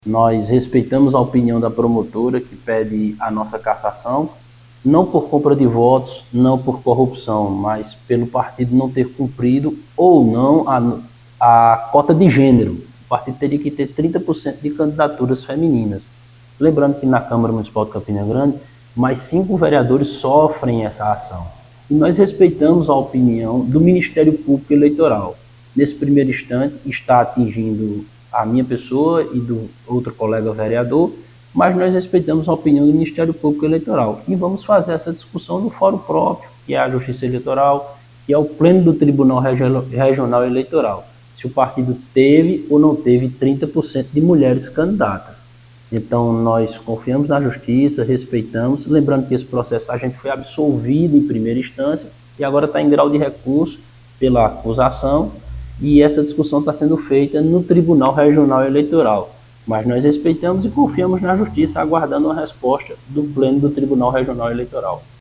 Em entrevista ao programa Correio Debate, da Rádio Correio 98 FM, desta segunda-feira (24/04), o parlamentar afirmou respeitar a opinião da promotora que pediu sua cassação, mas que vai recorrer da acusação de fraude na cota de gênero.